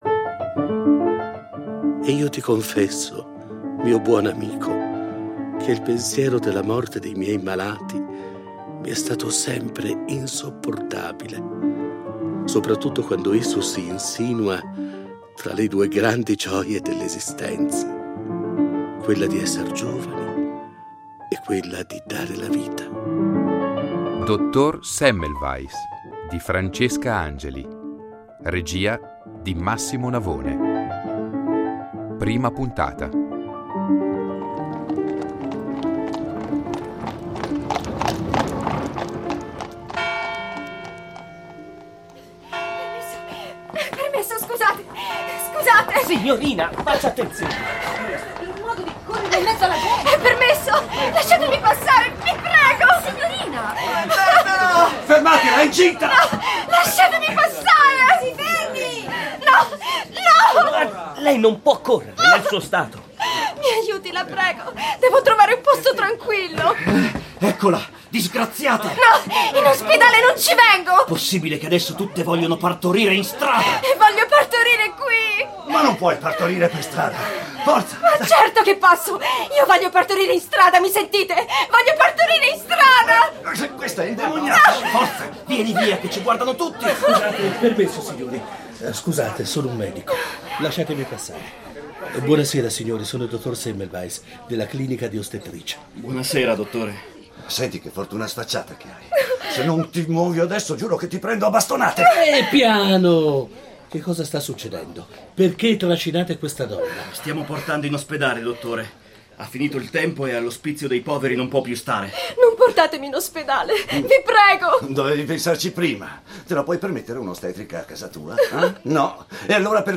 interpreta magistralmente la figura Filippo Ignazio Semmelweis, brillante medico della clinica imperiale di Vienna